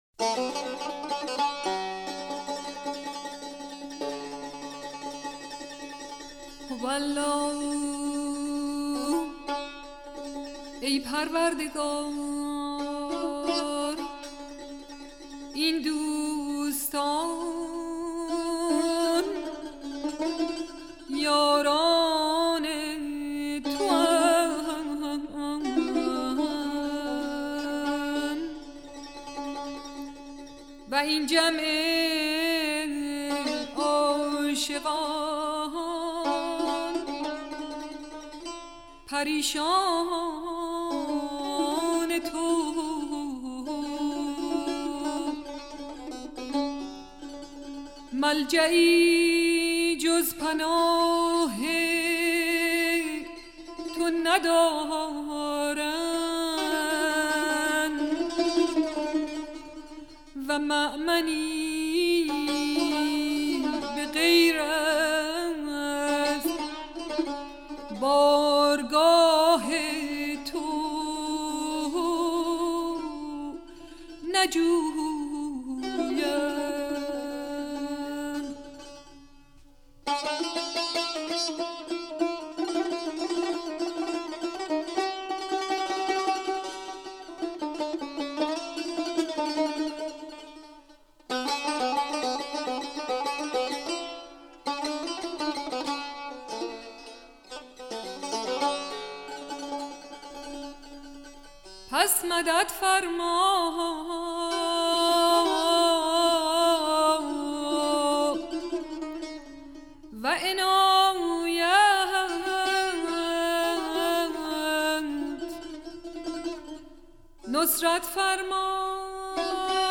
مجموعه ای از مناجات ها و اشعار بهائی (سنتّی)
این مناجاتها اونم با این صدای خوش و دل انگیز واقعا روح آدم و شاد میکنه و آرامش میده ممنون ای دوست در روضه قلب جز گل عشق مکار